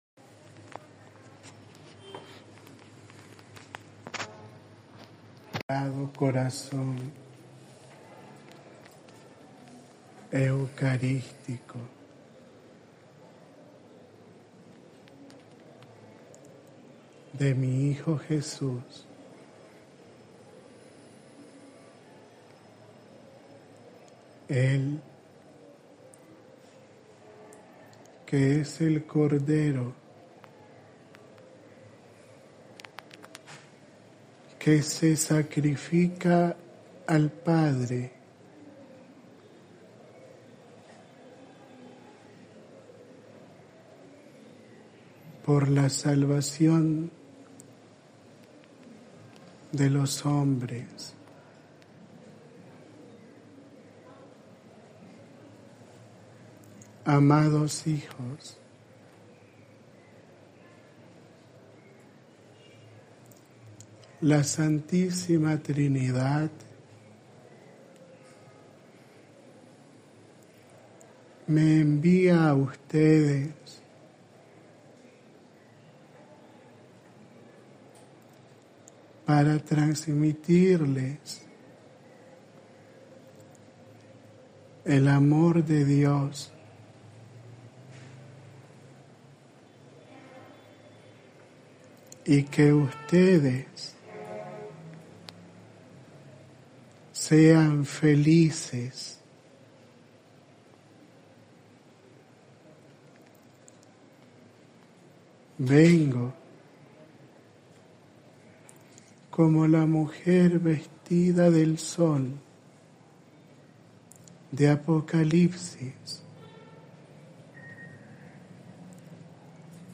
(Aparição e Mensagem durante a celebração do Décimo Segundo Aniversário da Revelação da Plenitude de todas as Advocações Marianas no Doloroso e Imaculado Coração de Maria e da Revelação do Apostolado.)
Áudio da Mensagem